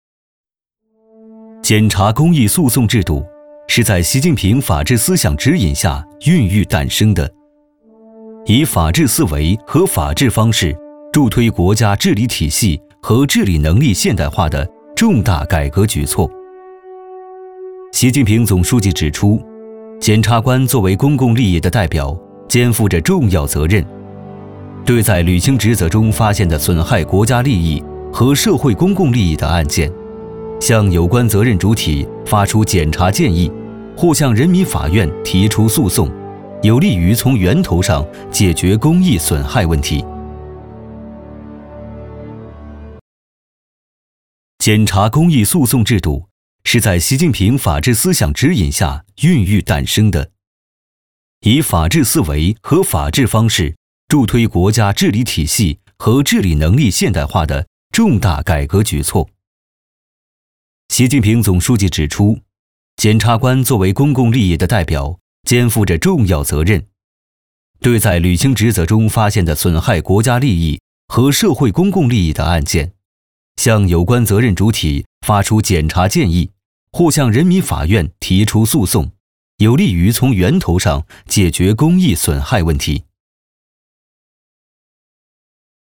男10号